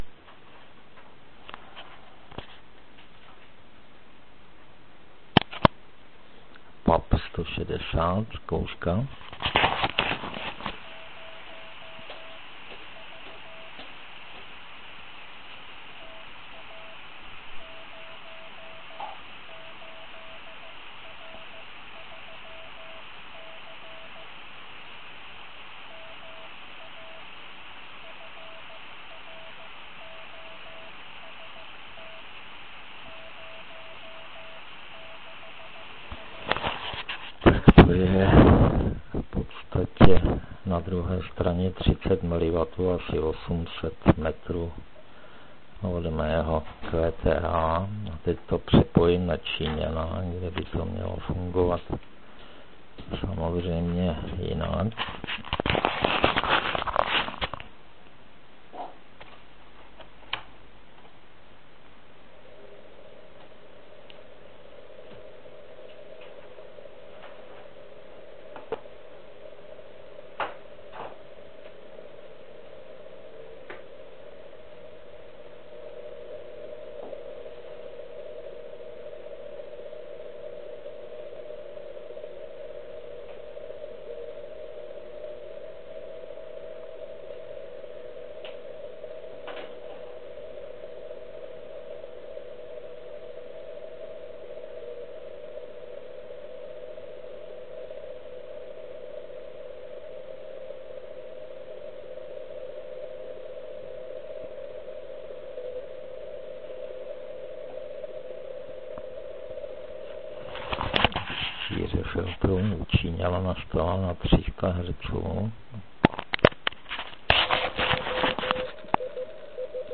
Asi 800m od mého QTH na zahrádce je puštěn maják cca 30mW do kusu drátu cca 15m, který je blbě přizpůsoben. Doma na velkou anténu to poslouchám střídavě na PUB-TK160 (Pixinu) a na čínský RS918. Nenechte se zmást frekvenční charakteristikou nahrávacího zařízení. Pochopitelně na RS918 je to lepší, ale Pixina se stydět nemusí. Je to čitelné.